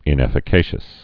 (ĭn-ĕfĭ-kāshəs)